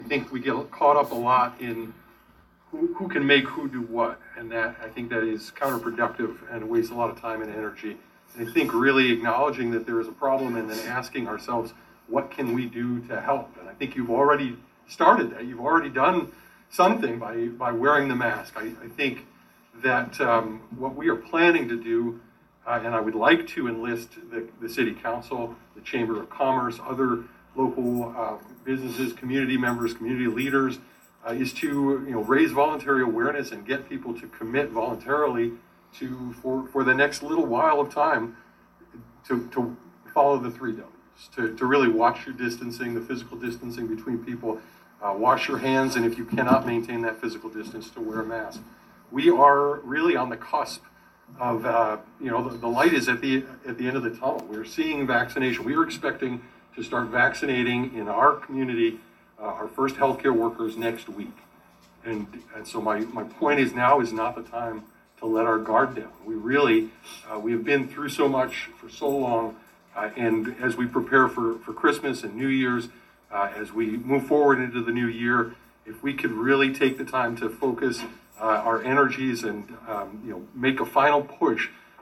Mobridge City Council discusses mask policies